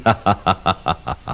deeplaugh.wav